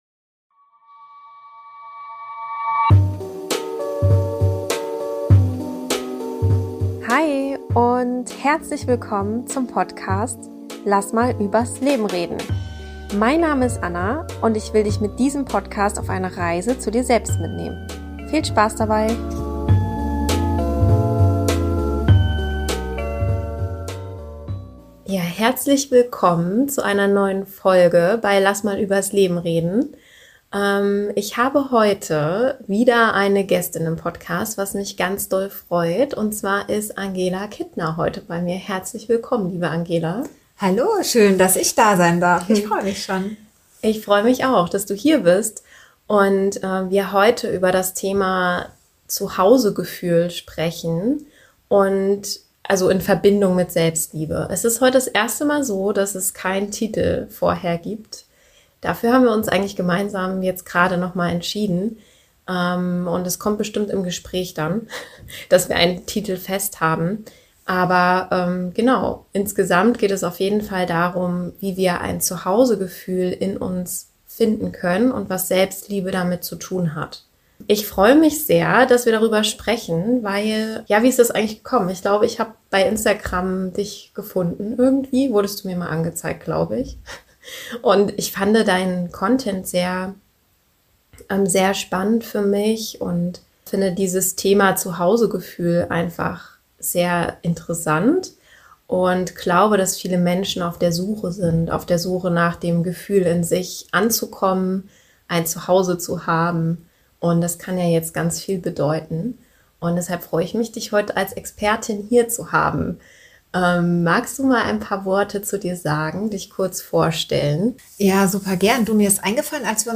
16: Zuhause-Gefühl durch Selbstfürsorge erschaffen- Interview